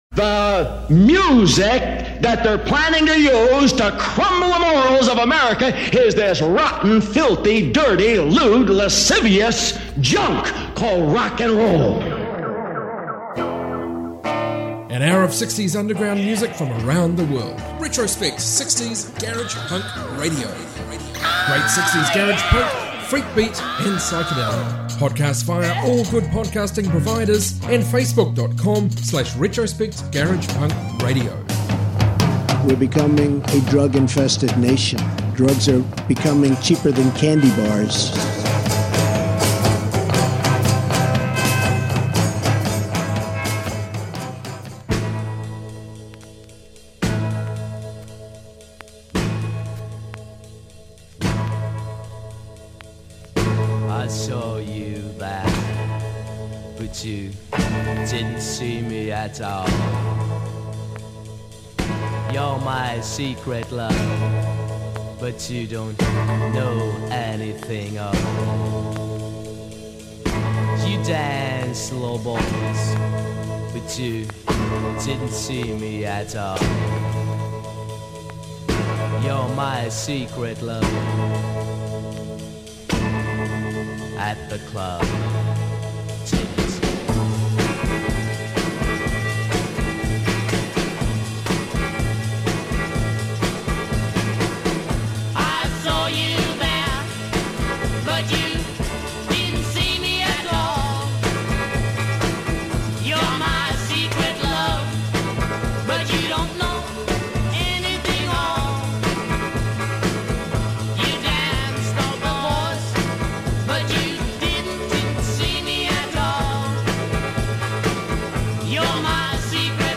60s garage rock garage punk protopunk freakbeat